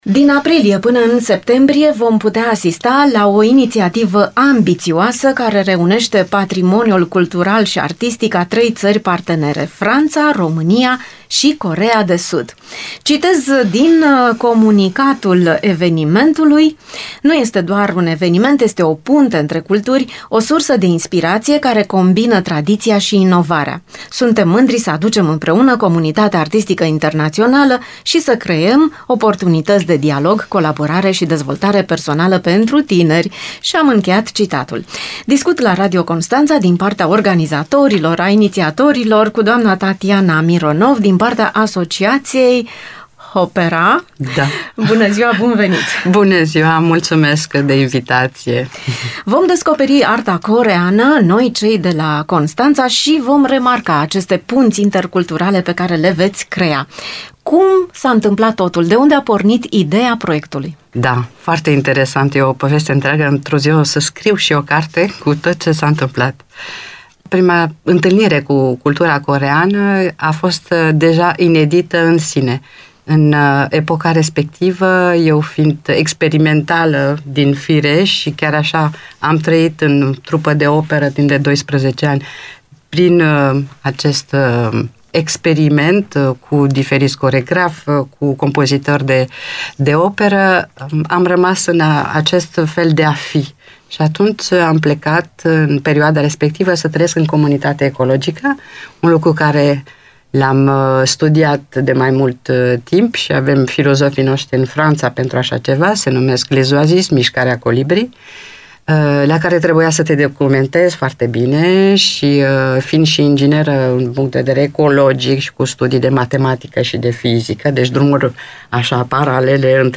dialogul